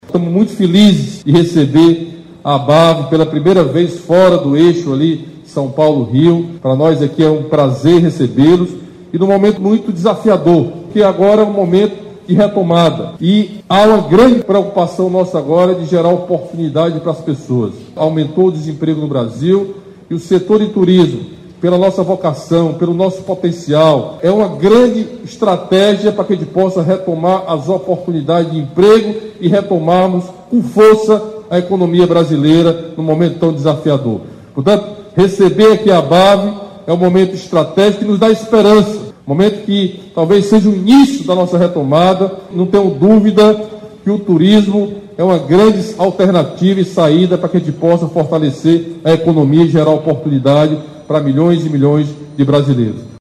Na abertura da ABAV Expo & Collab, governador destaca a retomada do Turismo no Ceará